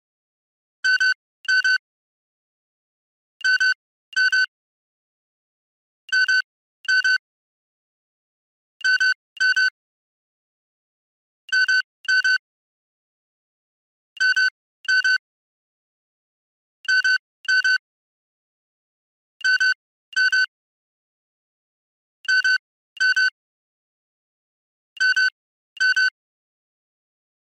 دانلود صدای رادار جت یا هواپیما از ساعد نیوز با لینک مستقیم و کیفیت بالا
جلوه های صوتی